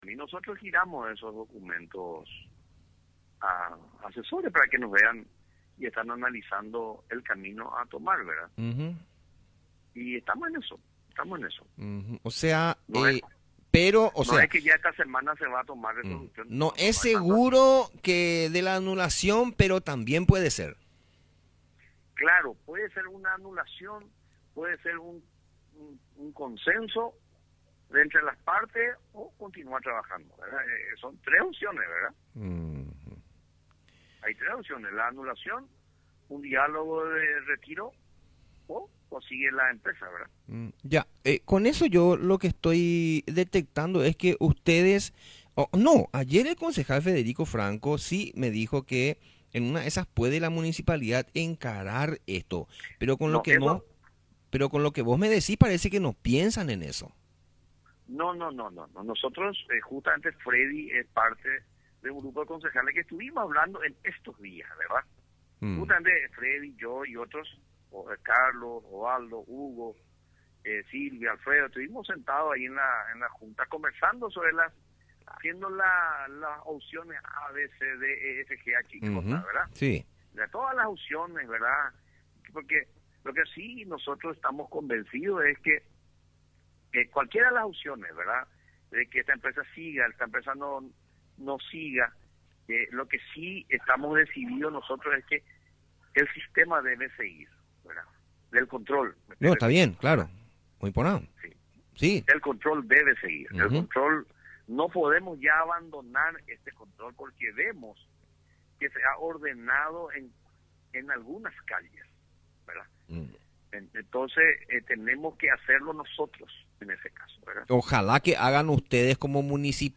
LO QUE DIJO EL EDIL NELSON PERALTA